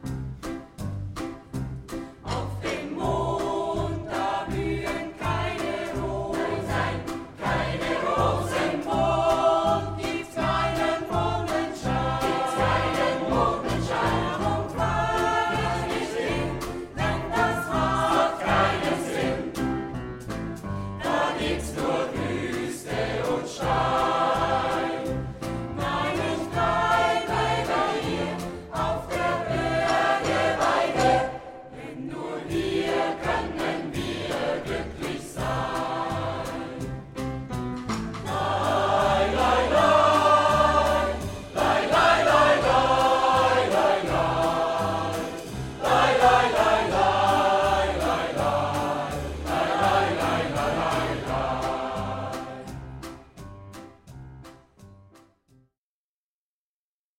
Band
SMATB